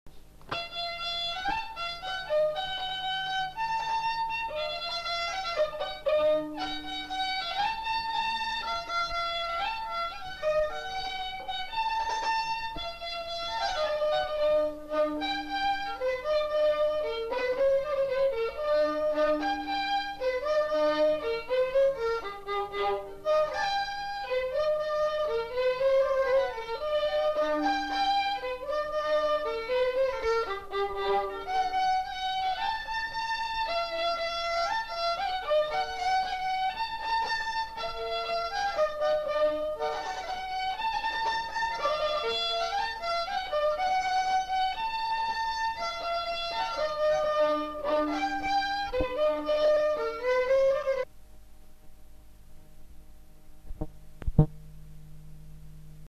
Aire culturelle : Lugues
Lieu : Saint-Michel-de-Castelnau
Genre : morceau instrumental
Instrument de musique : violon
Danse : polka
Notes consultables : 2 violons.